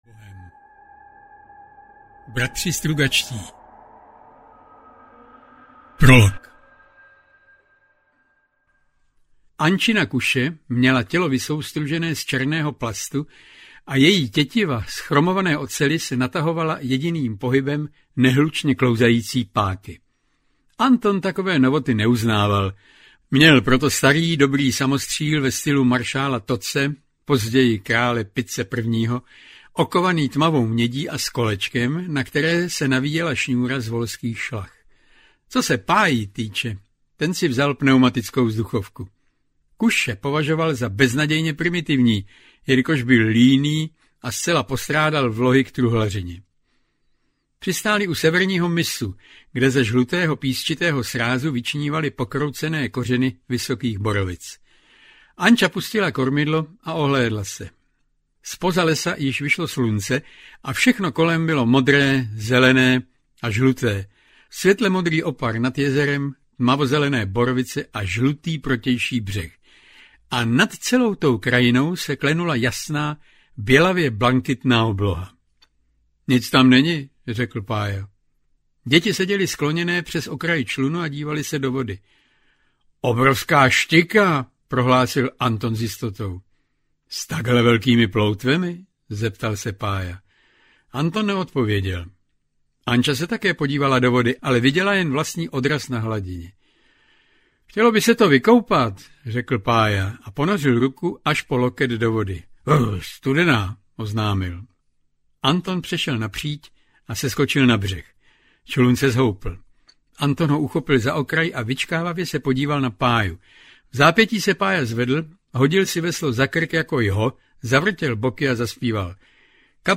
Je těžké být bohem audiokniha
Ukázka z knihy